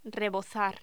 Locución: Rebozar
voz
Sonidos: Voz humana